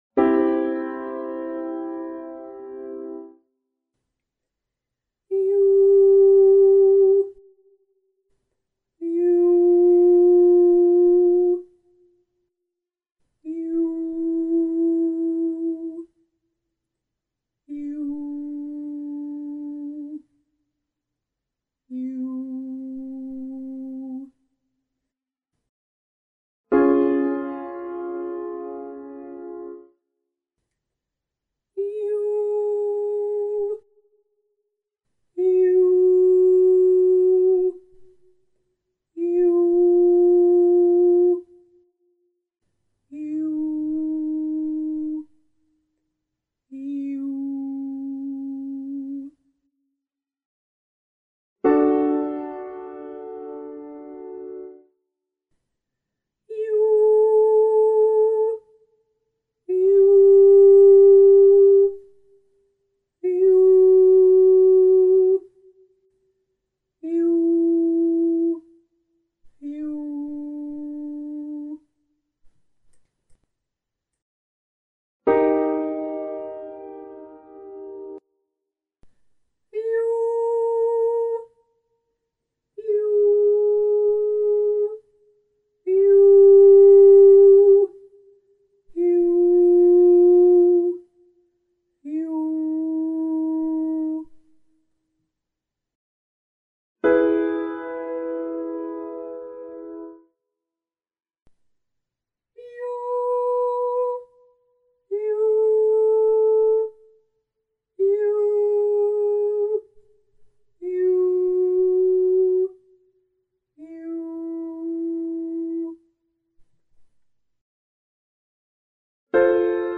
27A Half Note Breathing on /yoo/